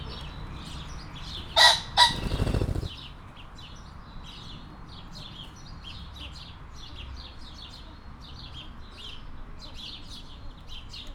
Directory Listing of /_MP3/allathangok/kecskemetizoo2016_professzionalis/vadaszfacan/